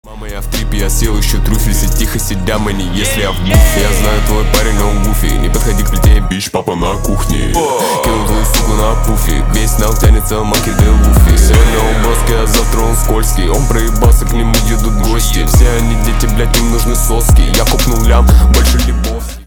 басы
рэп